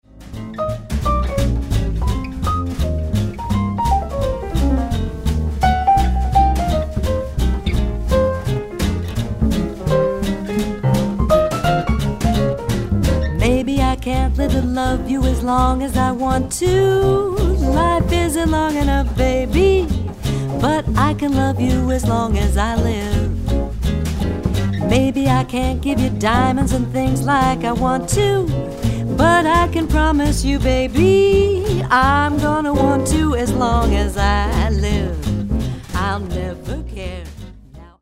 vocals, guitar
trumpet, flugelhorn
piano
bass
drums